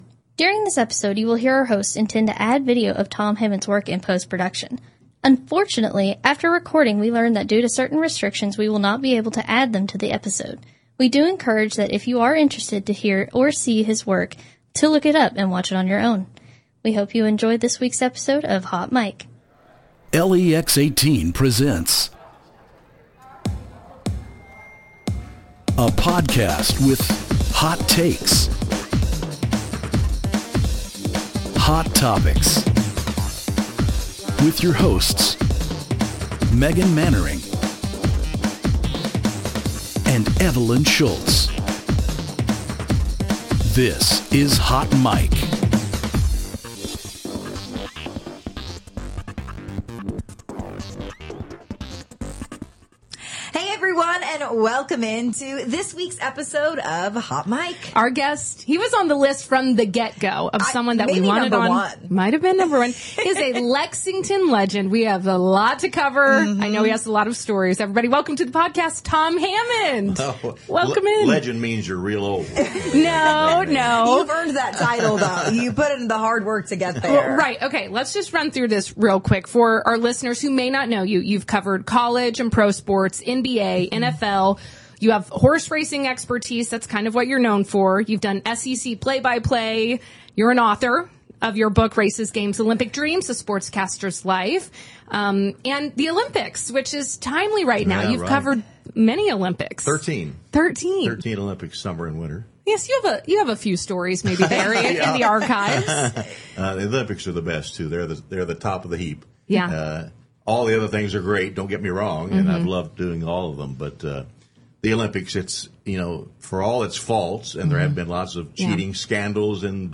This conversation with Tom Hammond, a legendary sportscaster, transcends a mere recounting of his career highlights.